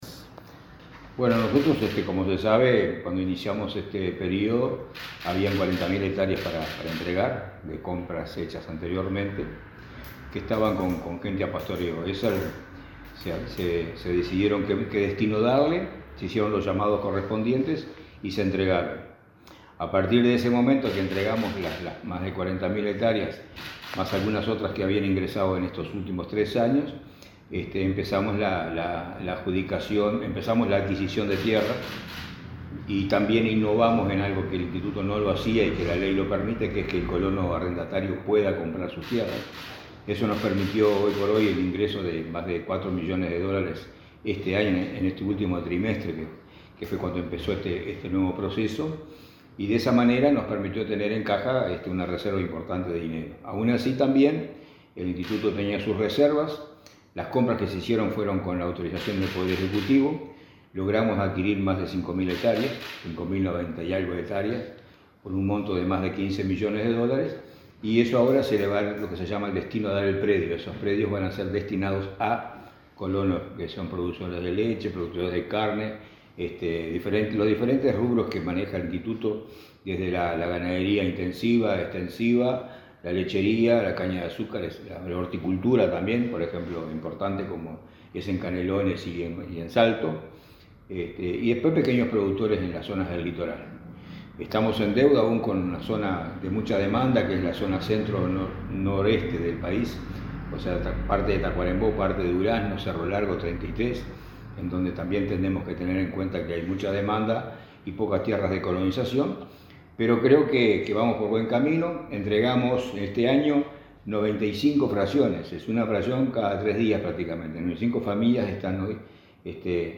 Entrevista al presidente del Instituto Nacional de Colonización, Julio Cardozo